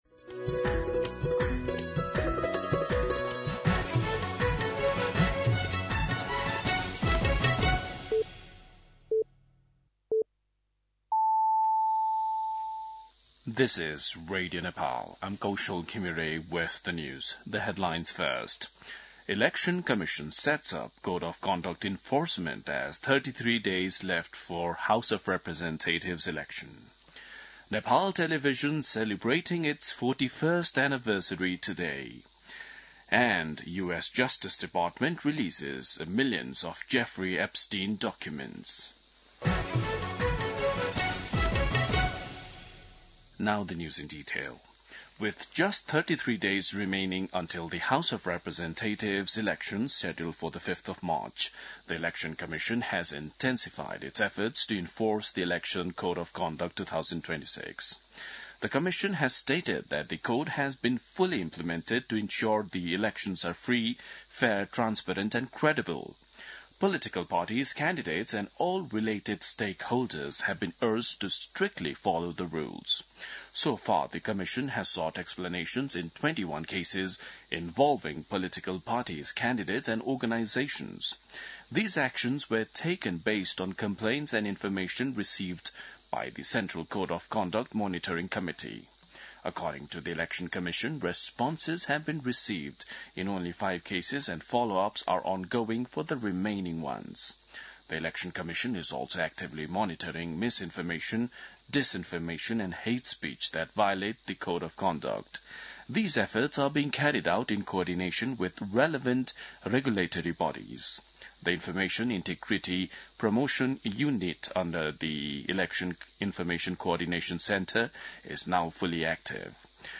दिउँसो २ बजेको अङ्ग्रेजी समाचार : १७ माघ , २०८२